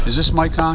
Play, download and share Is this mic on original sound button!!!!
is-this-mic-on.mp3